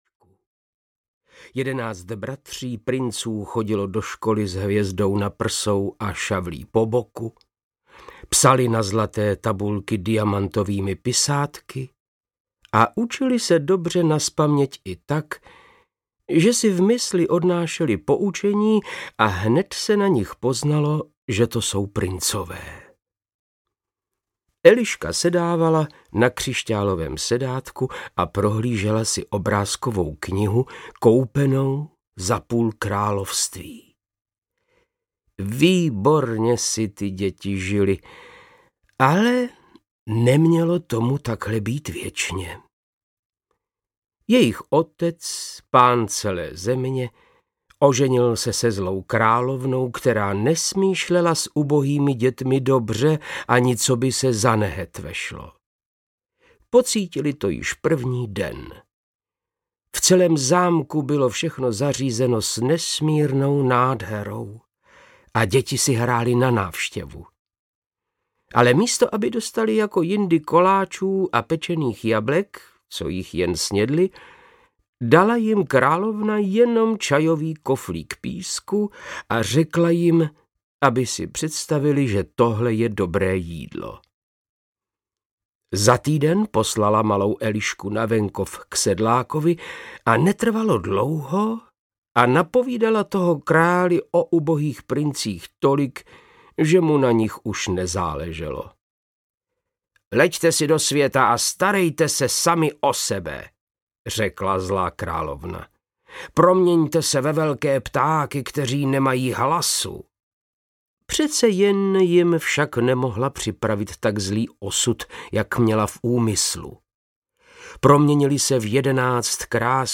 Divoké labutě audiokniha
Ukázka z knihy
• InterpretVáclav Knop